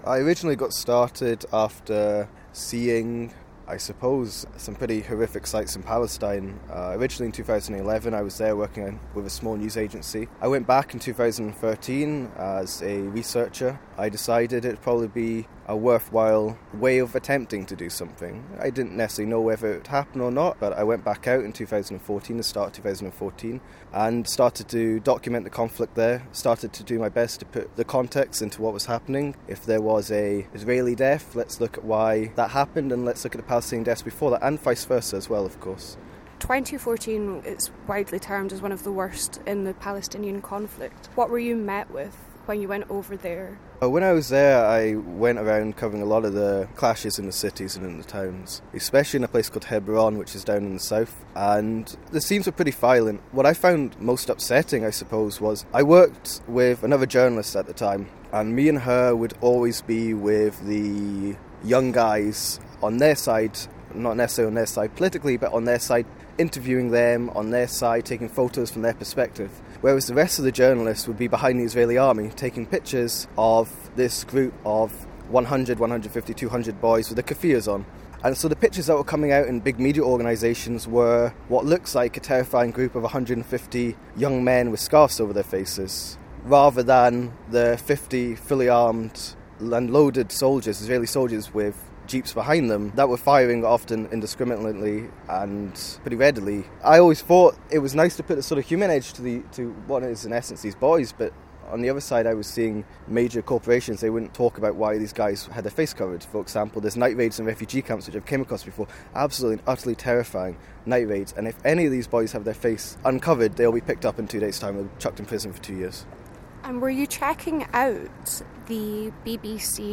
How I Became A Conflict Journalist: An investigative interview